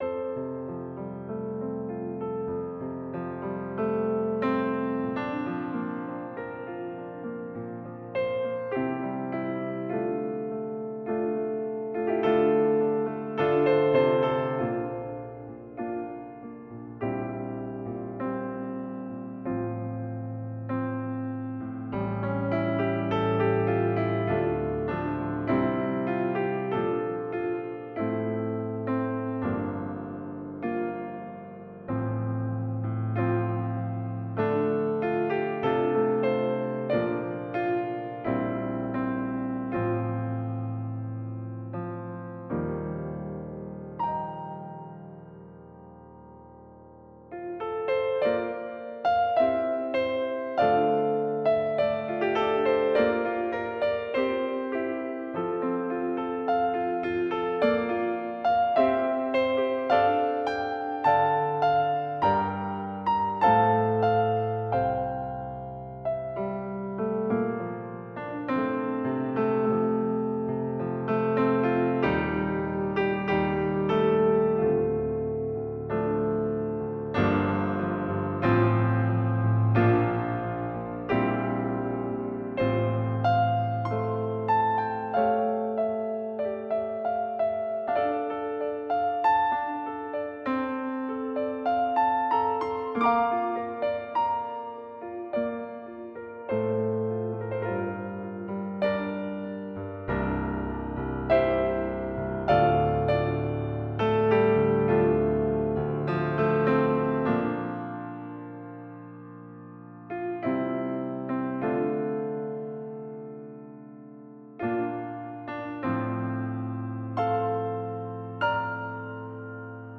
An improvised music tribute